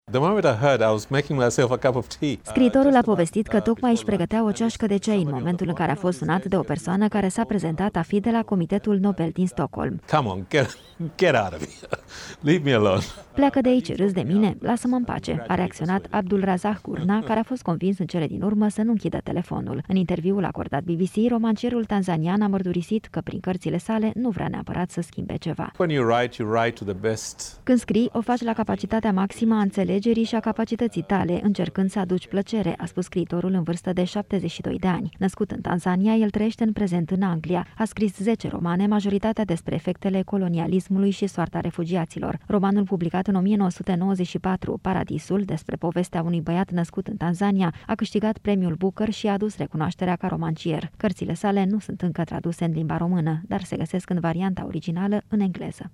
Într-un interviu acordat BBC, scriitorul a vorbit despre acest moment și despre munca sa.